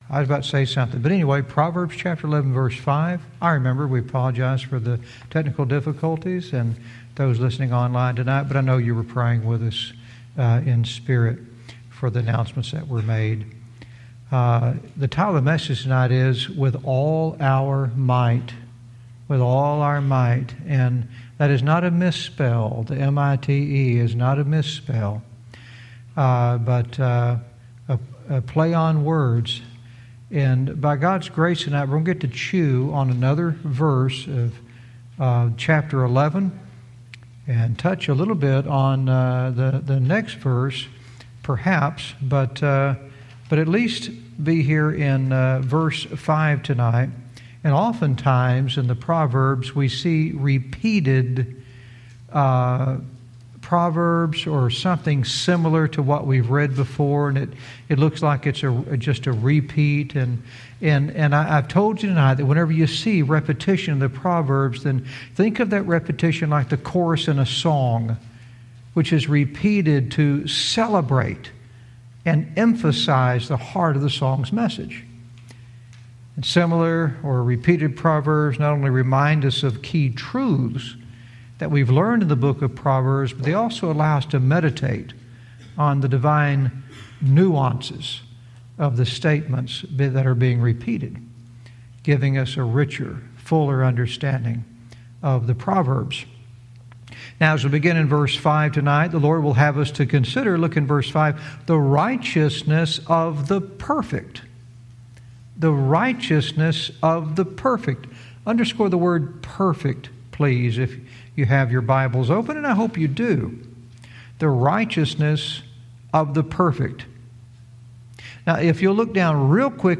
Verse by verse teaching - Proverbs 11:5 "With All Our Mite"